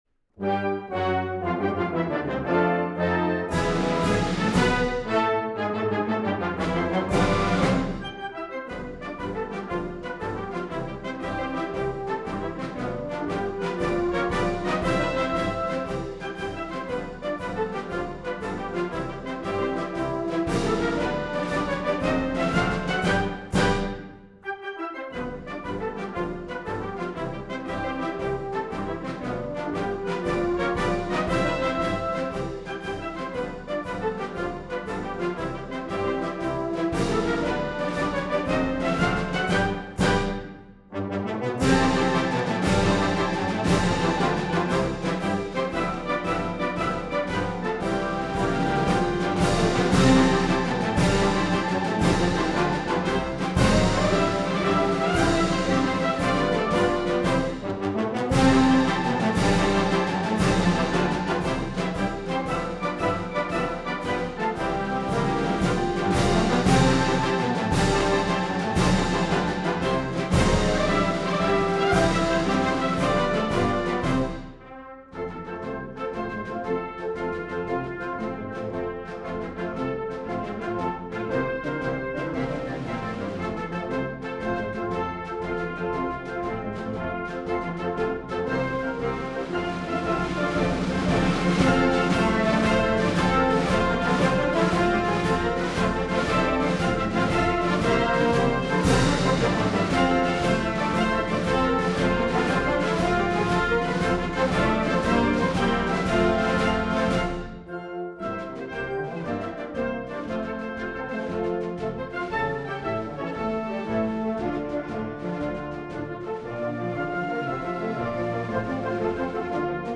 March